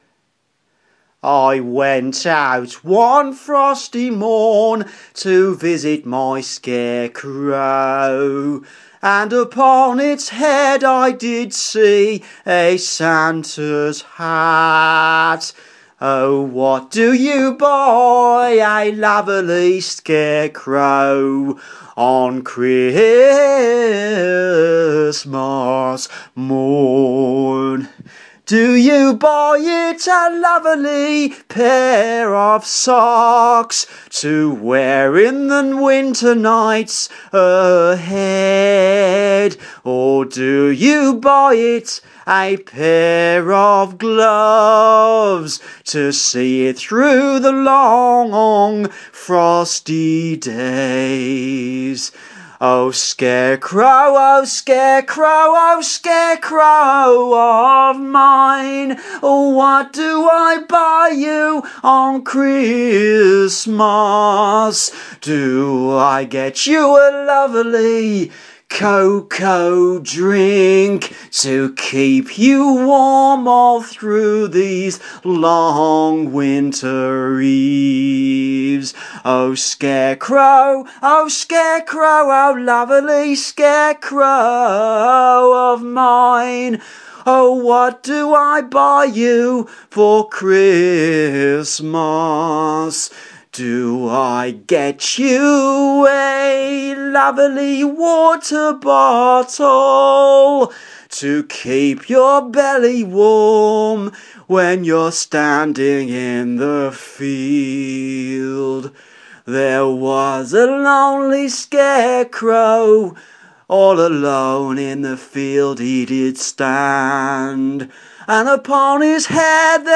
Santacrow - a folk homage to the frosty scarecrow.